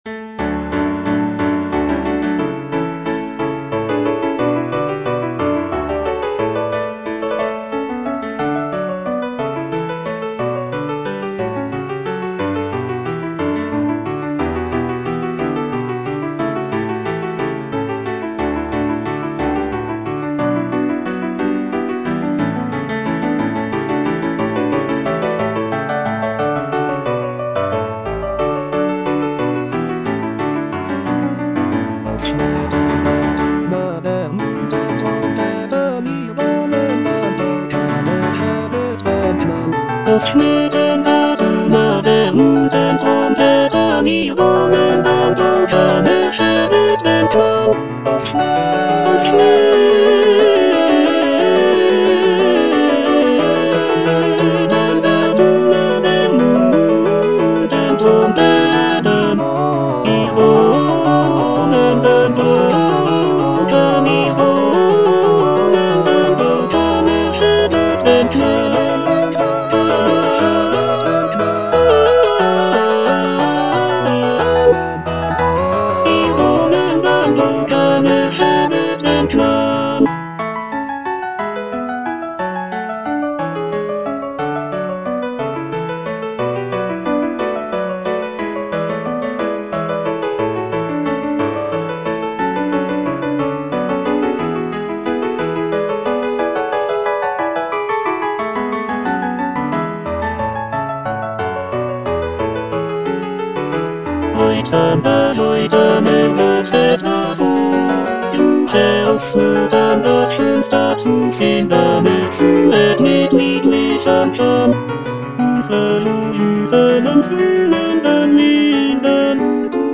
Cantata
Tenor (Emphasised voice and other voices) Ads stop